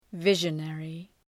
Προφορά
{‘vıʒə,nerı}